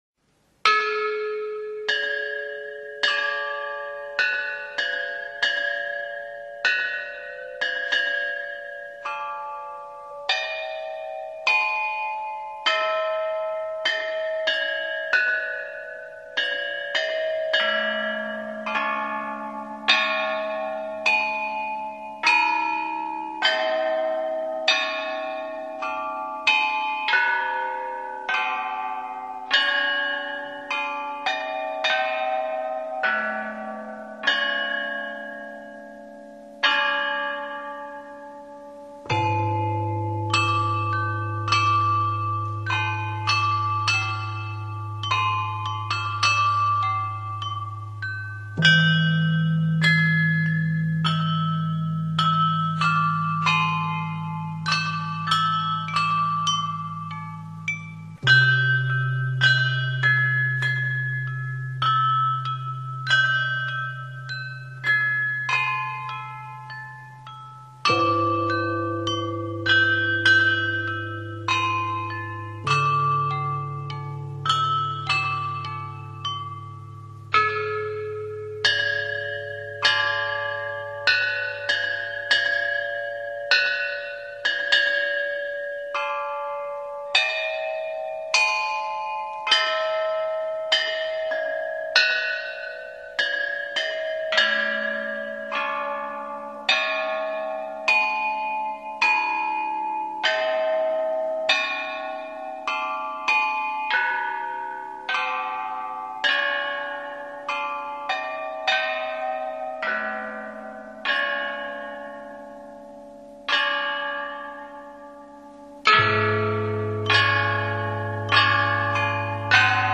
全套鍾含有深沈渾厚 的低音、鏗鏘圓潤的中音、清脆明亮的高音，豐富的色彩可調配出相當的表現力。